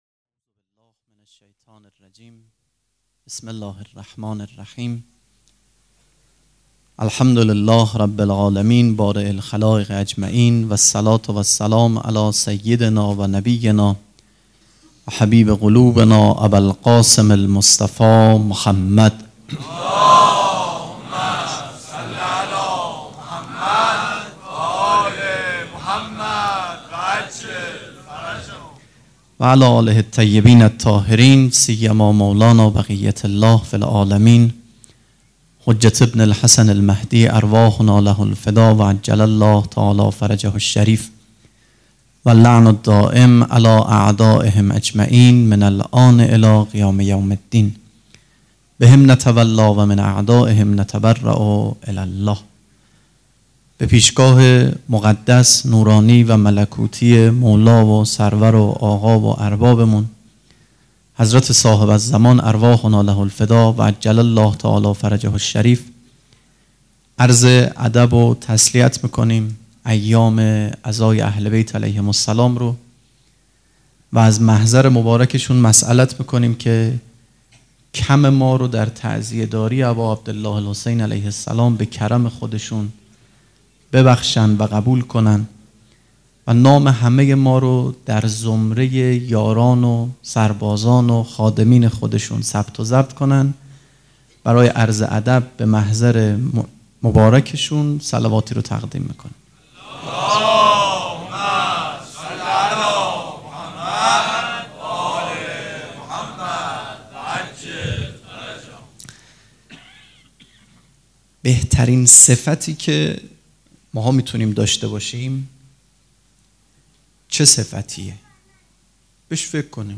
سخنرانی
هیئت ریحانه الحسین سلام الله علیها
sokhanrani.mp3